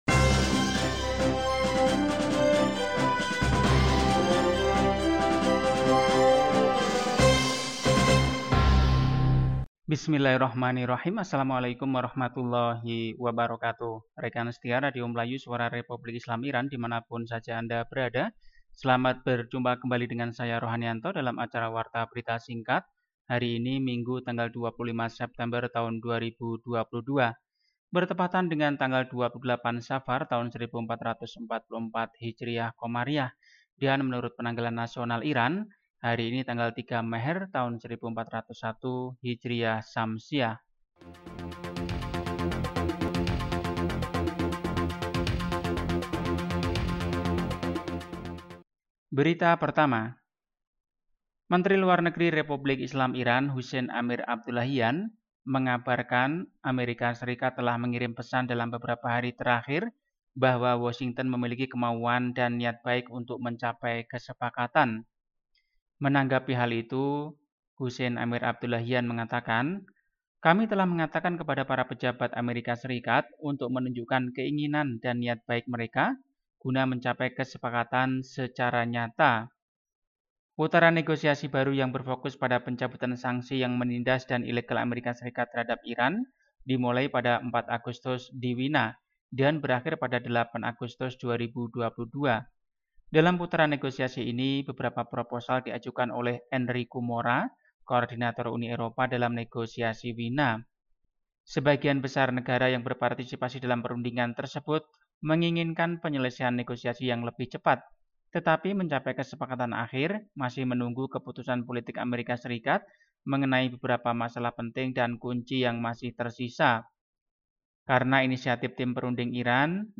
Warta Berita 25 September 2022